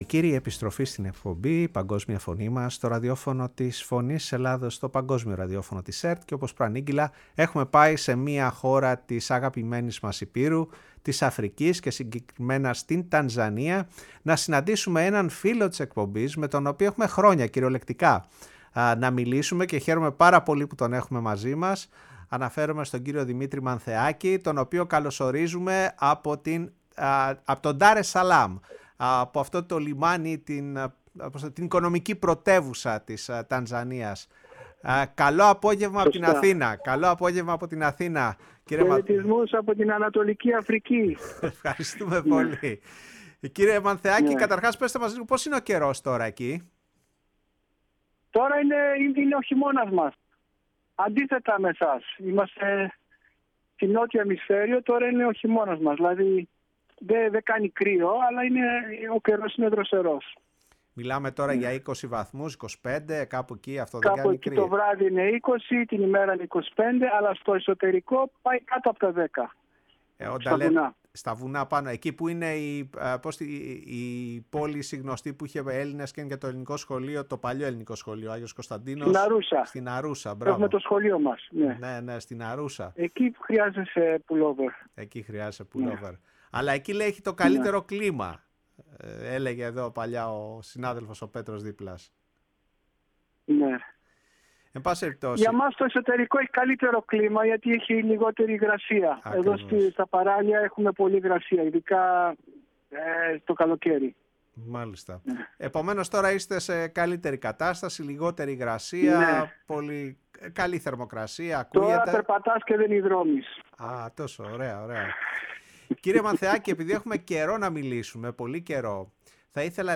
μιλώντας στο Ραδιόφωνο της Φωνής της Ελλάδας